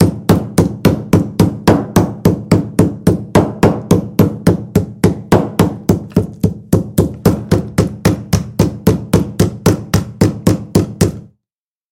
Звук отбивания мяса молотком